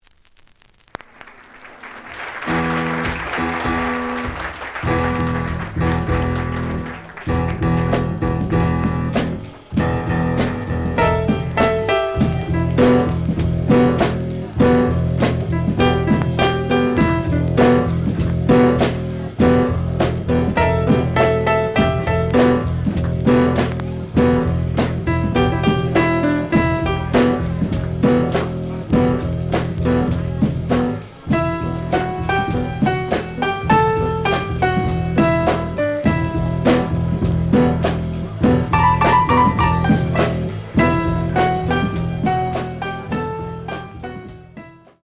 The audio sample is recorded from the actual item.
●Genre: Jazz Funk / Soul Jazz